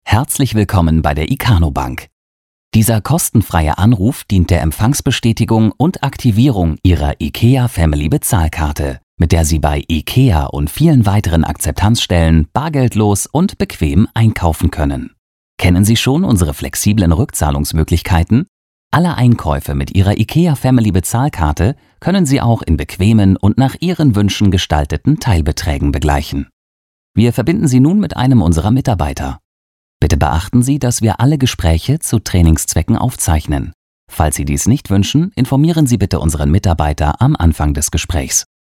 Meine Stimme: Frisch, lebendig, klar, präzise, freundlich, gefühlvoll, warm, verbindlich, seriös.
• Mikrofone: Neumann U87 Ai & TLM 103
Telefon
DEMO-Telefonansage.mp3